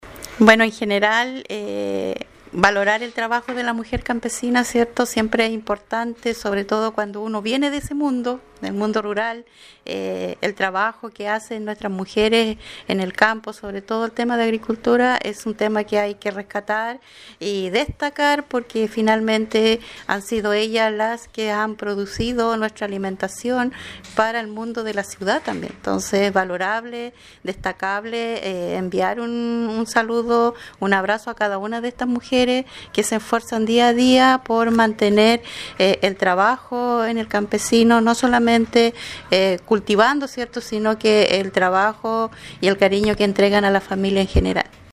Así lo manifestó  una representante de las mujeres rurales del territorio: Edith Canío, agricultora, artesana, dirigenta huilliche y concejala de la comuna de San Pablo